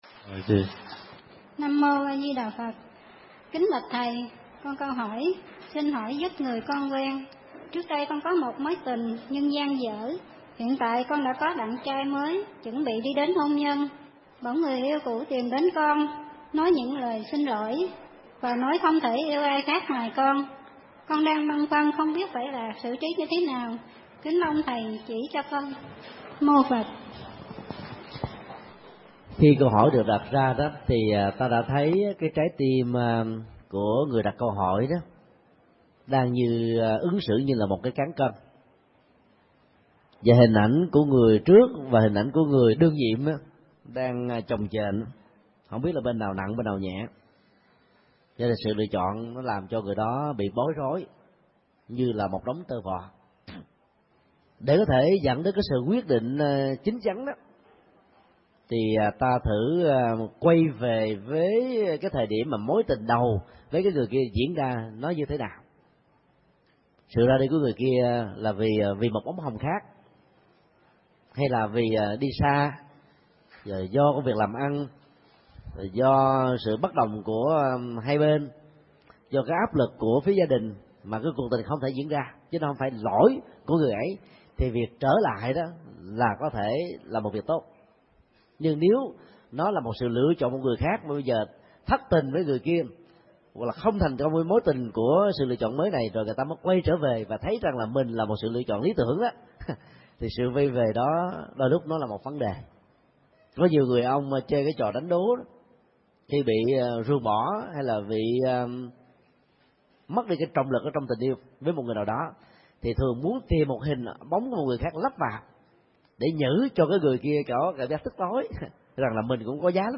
Vấn đáp: Chọn hạnh phúc hôn nhân giữa người cũ và người mới